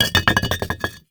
CONSTRUCTION_Bricks_Fast_01_loop_mono.wav